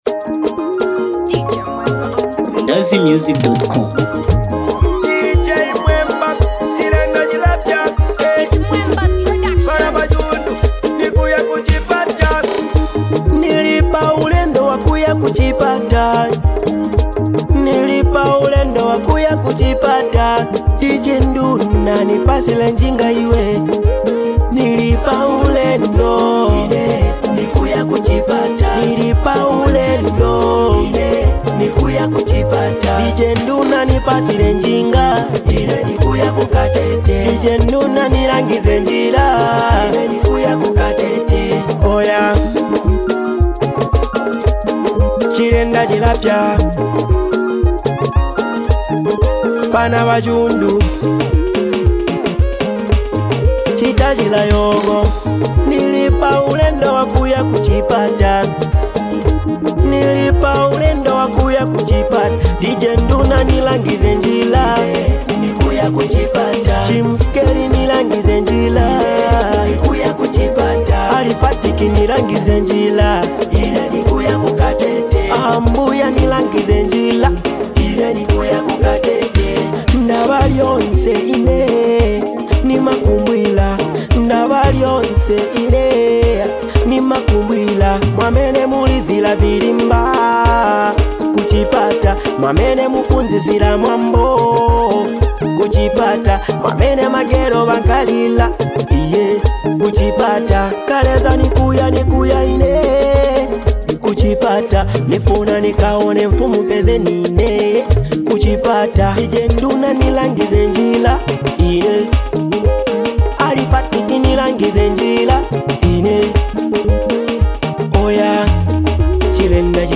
it’s Dancehall jam.